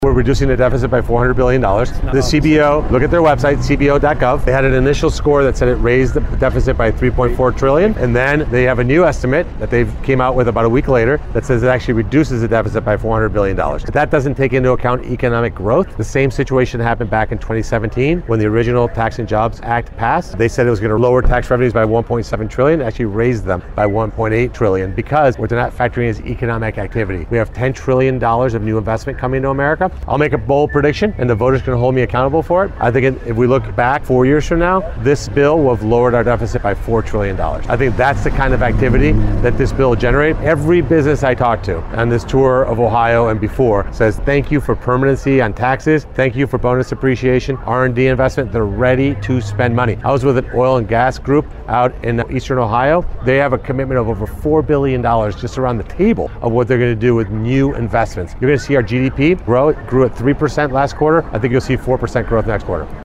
WCSM Headline News